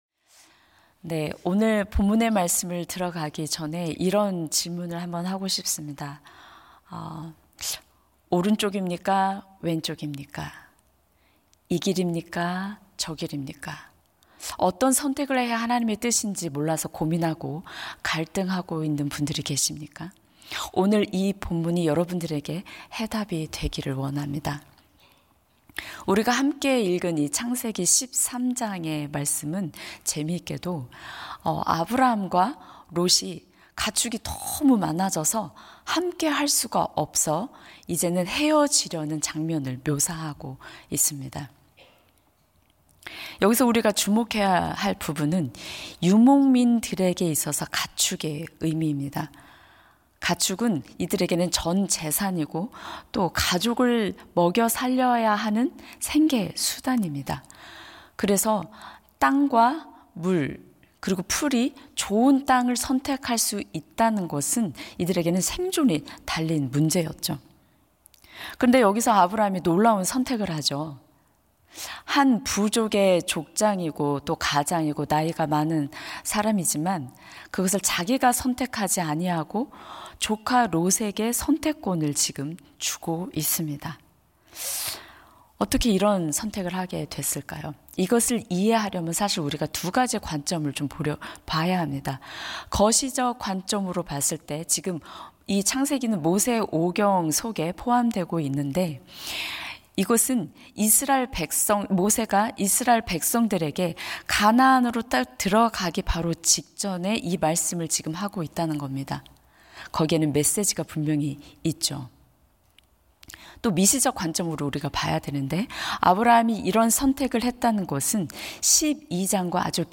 예배 새벽예배